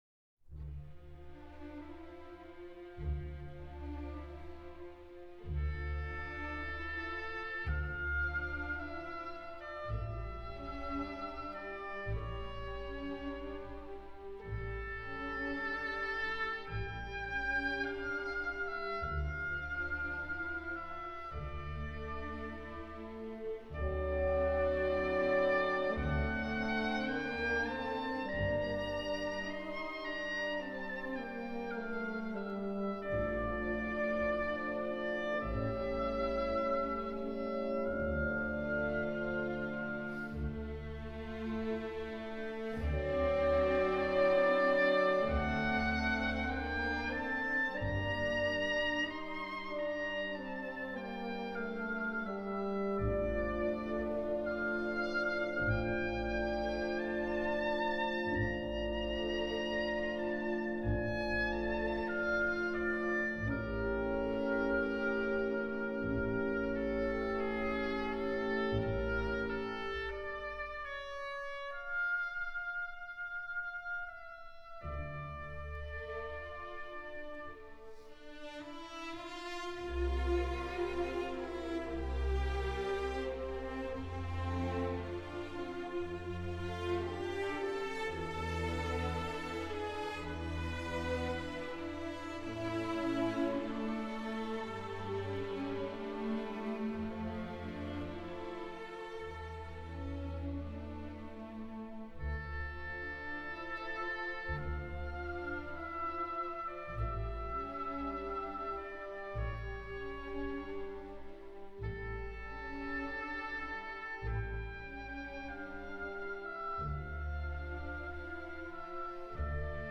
Clásica